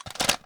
ar_mag_unload.ogg